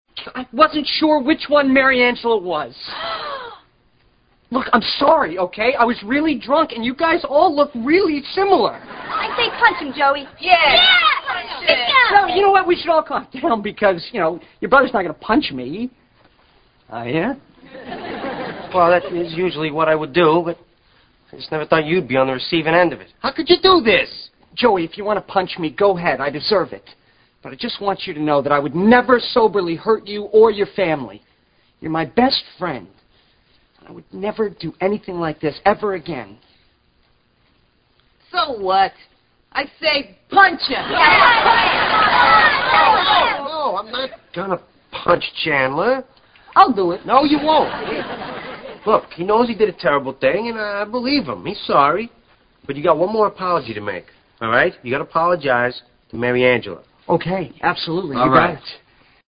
同时Chandler在说在这句话时，也把重音放在了这个ever之上，更显示出自己的诚意与决心。